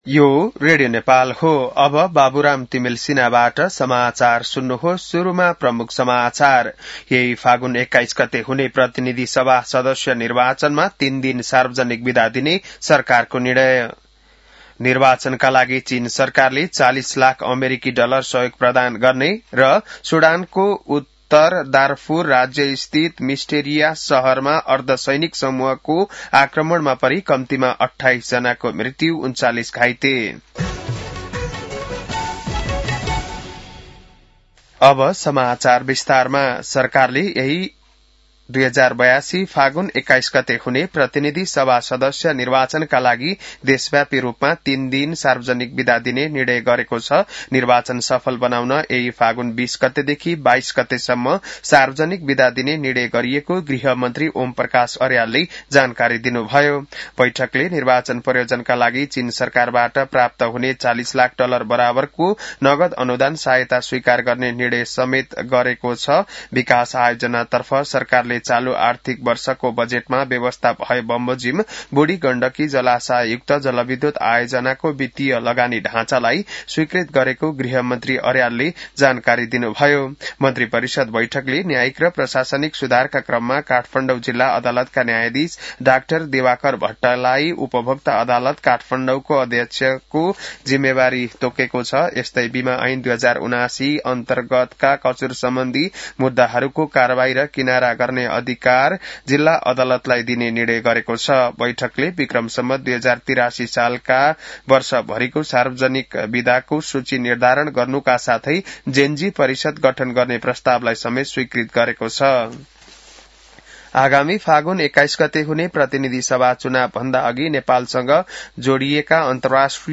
बिहान ९ बजेको नेपाली समाचार : १३ फागुन , २०८२